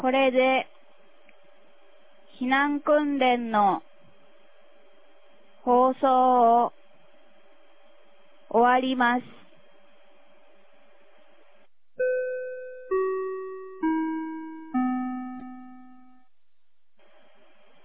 2023年11月05日 09時02分に、南国市より放送がありました。